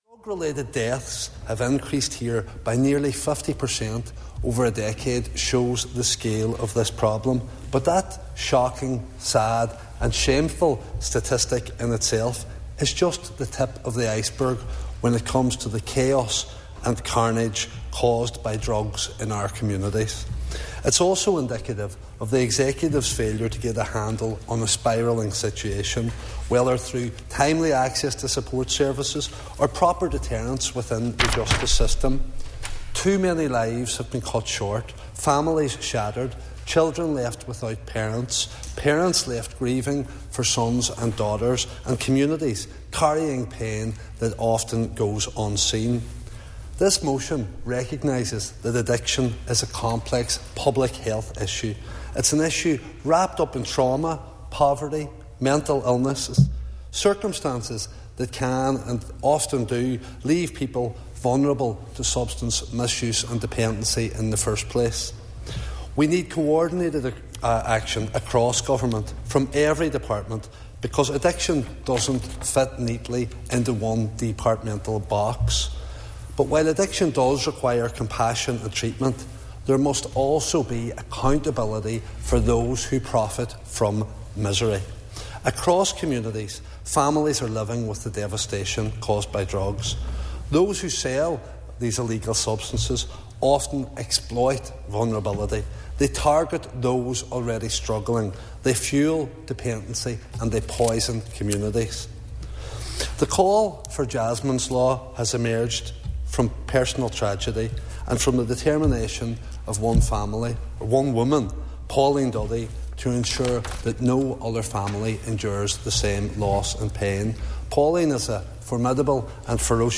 Mark H. Durkan says that sentencing needs to be a real deterrent: